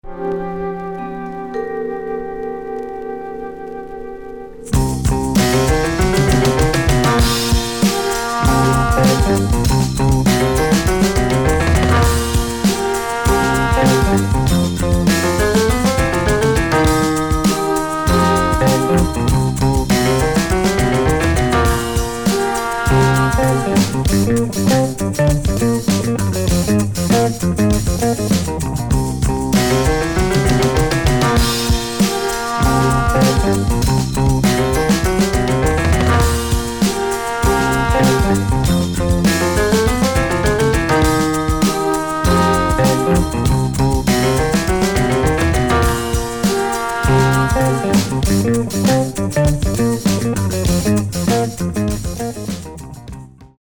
Two top funk tunes the Italian way